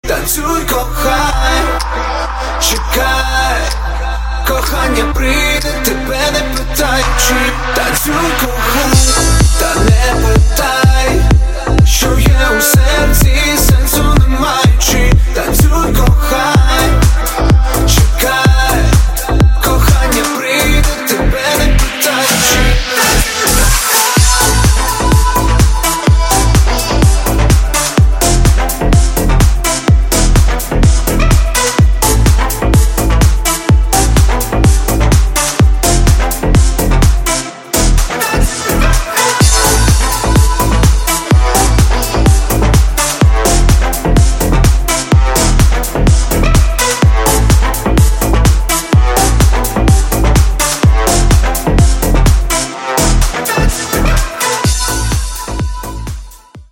• Качество: 128, Stereo
громкие
dance
Club House
українська клубна музика